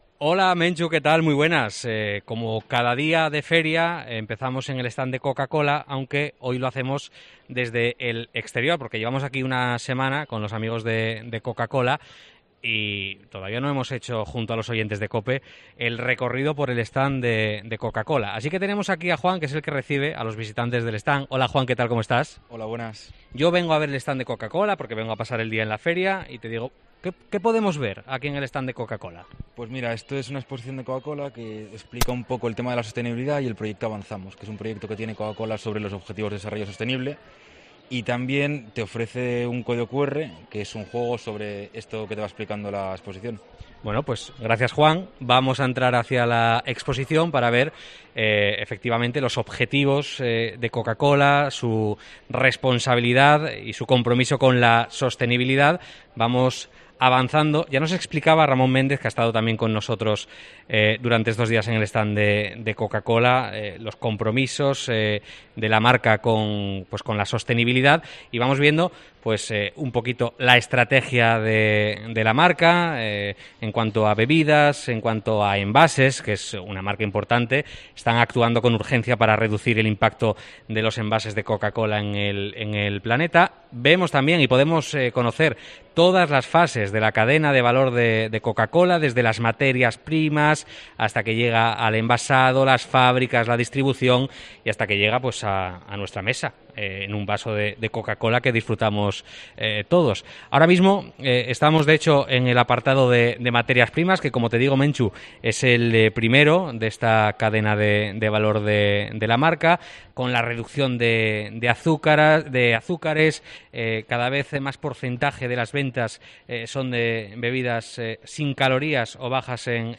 Recorrido por el stand de Coca-Cola en la Feria de Muestras